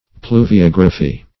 pluviography - definition of pluviography - synonyms, pronunciation, spelling from Free Dictionary
Search Result for " pluviography" : The Collaborative International Dictionary of English v.0.48: Pluviography \Plu`vi*og"ra*phy\, n. [L. pluvia rain + -graphy.]
pluviography.mp3